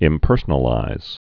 (ĭm-pûrsə-nə-līz)